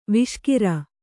♪ viṣkira